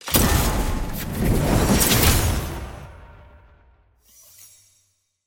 sfx-tier-wings-promotion-to-bronze.ogg